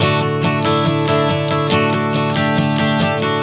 Strum 140 D 01.wav